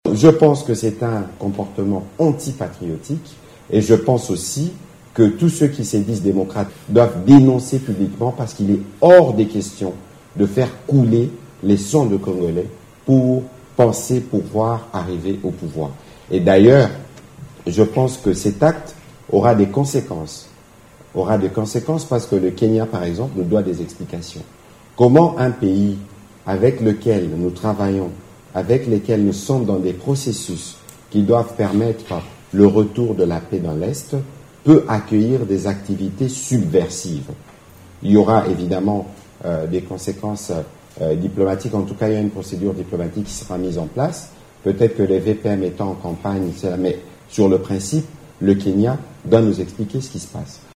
Au cours d’une conférence de presse tenue le vendredi 15 décembre à Kinshasa, Patrick Muyaya traite le comportement de Corneille Naanga d’antipatriotique.